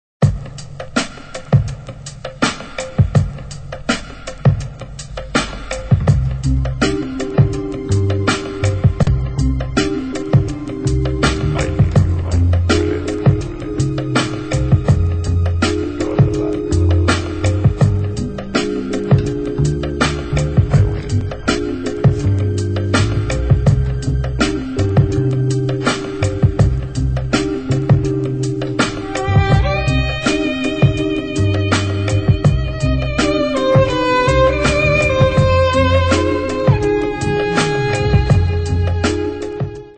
Moderne Tangos/ Tango-Atmosphäre